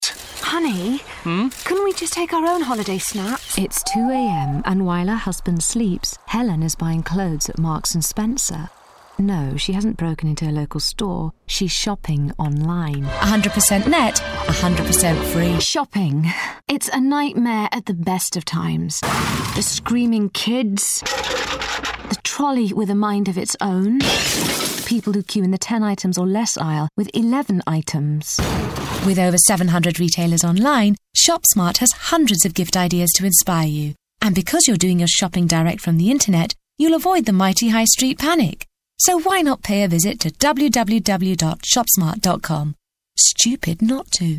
Commercial Showreel
Indian, London, RP ('Received Pronunciation')